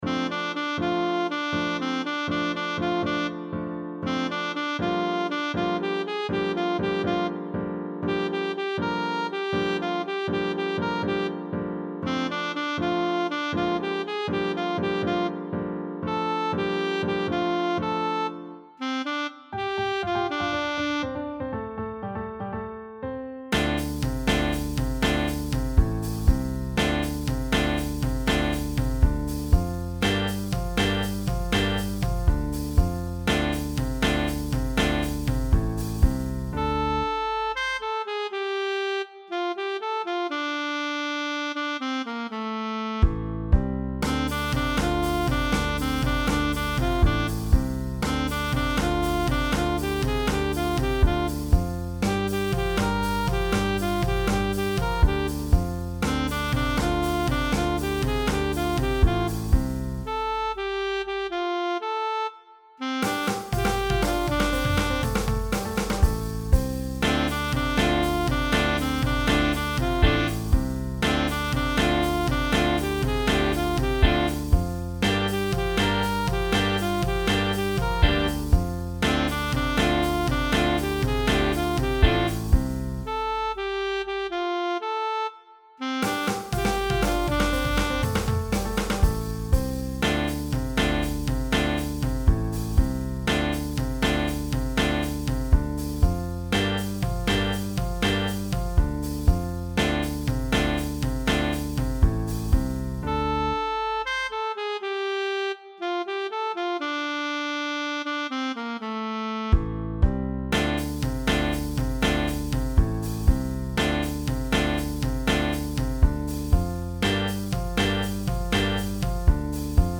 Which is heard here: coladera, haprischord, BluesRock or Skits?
BluesRock